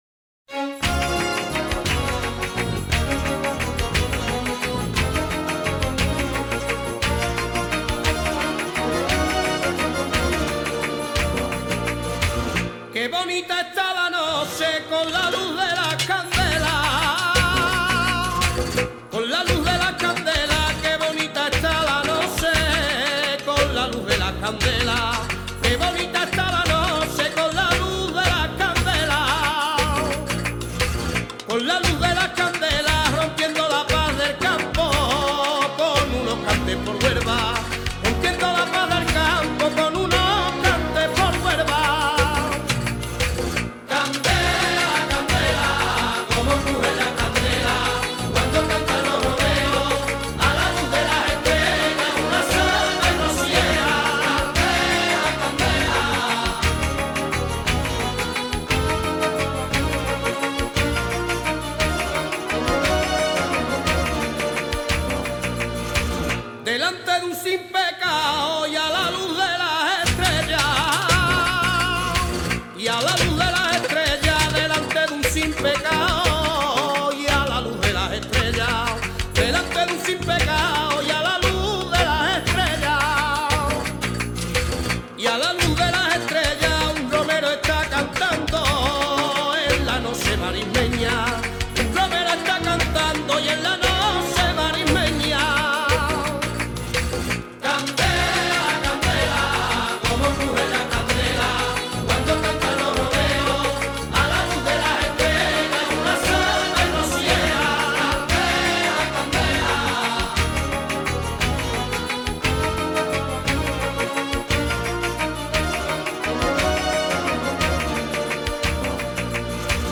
Sevillanas
poseer una voz llena de matices